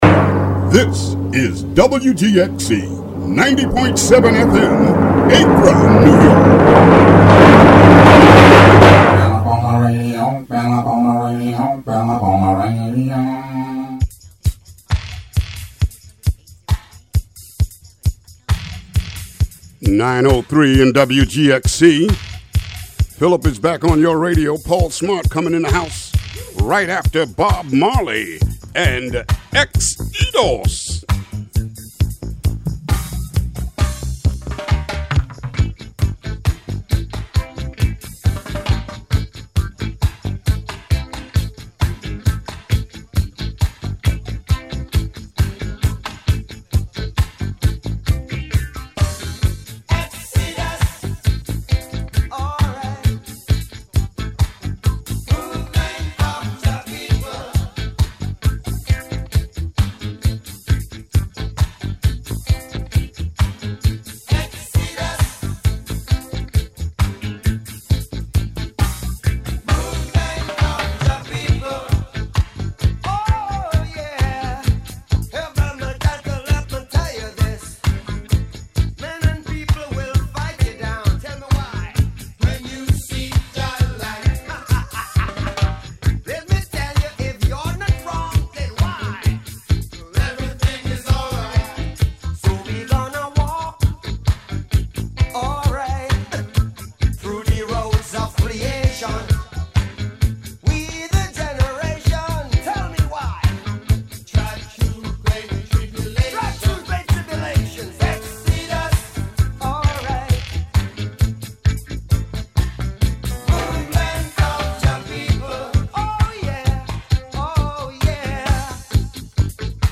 WGXC Morning Show Contributions from many WGXC programmers.